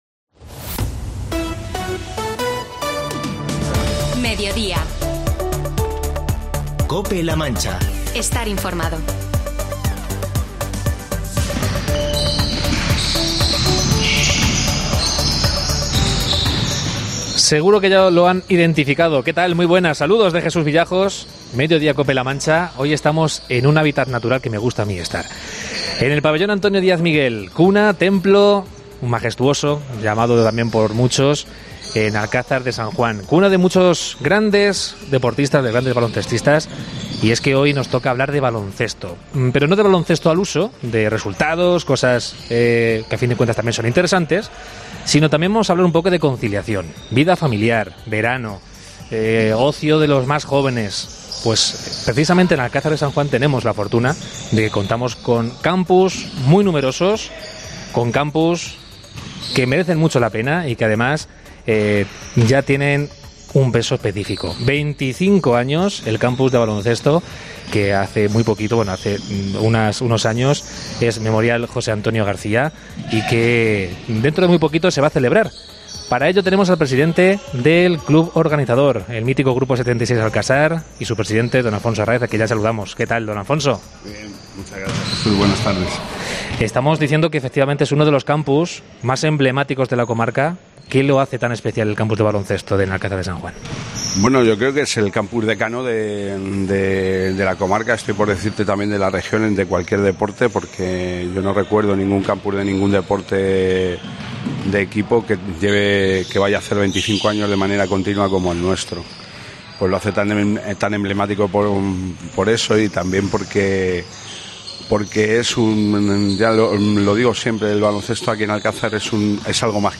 En Cope La Mancha Entrevista